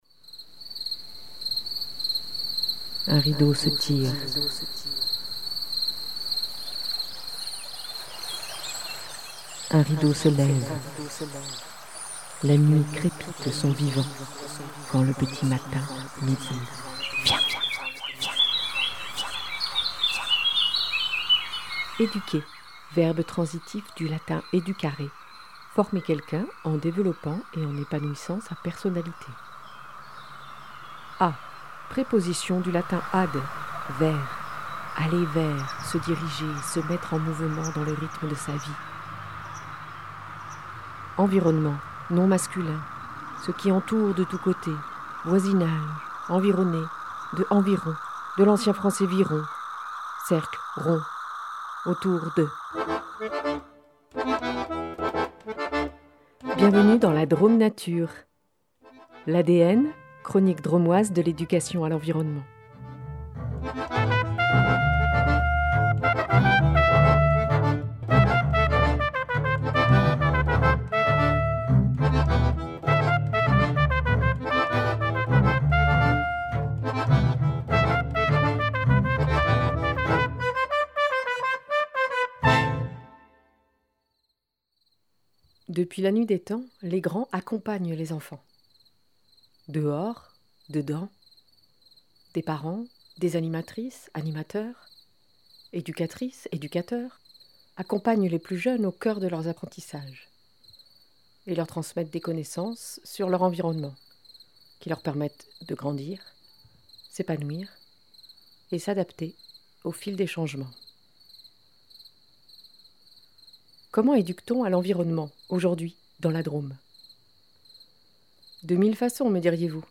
Émission Drômoise de l’Éducation à l’Environnement.